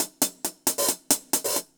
Index of /musicradar/ultimate-hihat-samples/135bpm
UHH_AcoustiHatB_135-01.wav